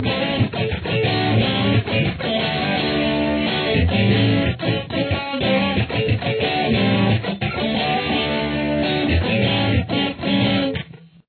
It gives it a percussion-like sound.
Main Riff